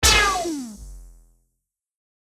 / F｜演出・アニメ・心理 / F-30 ｜Magic 魔法・特殊効果
レーザー 5-1Shotビューン